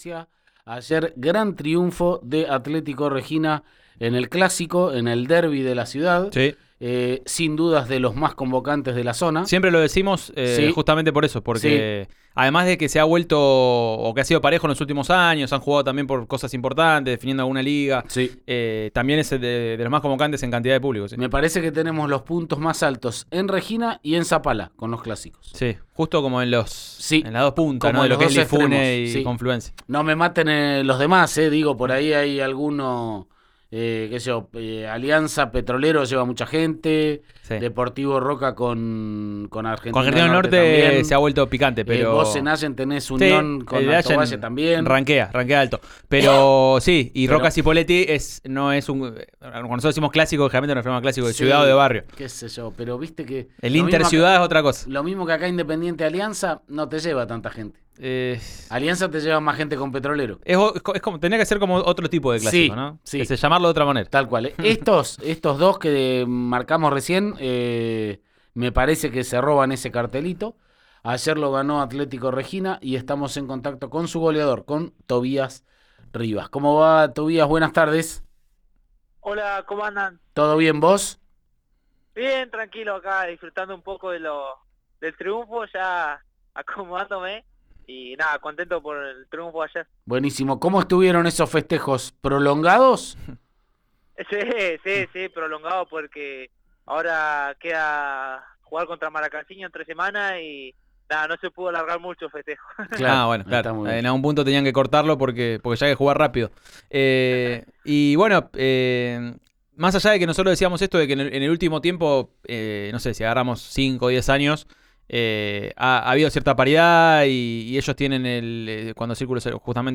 en diálogo con «Subite al Podio» de Río Negro Radio.